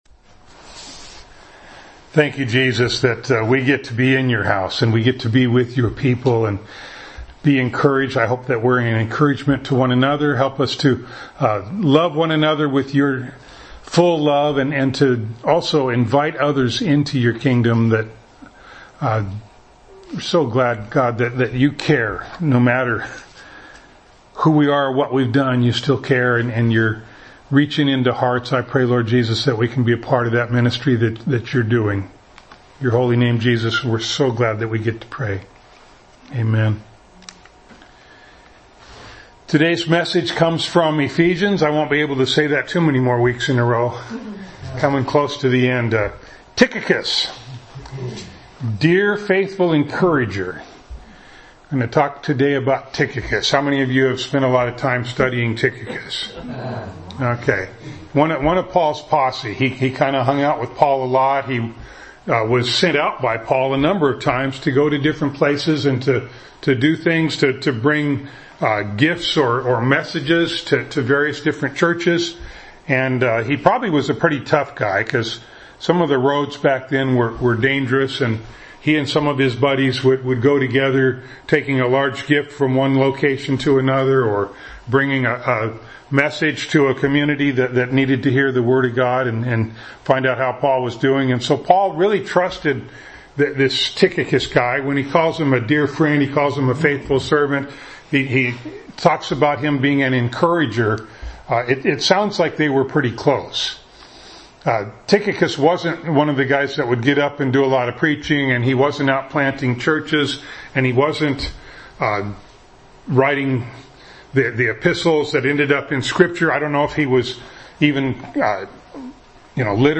Ephesians 6:21-22 Service Type: Sunday Morning Download Files Notes « Pray for Me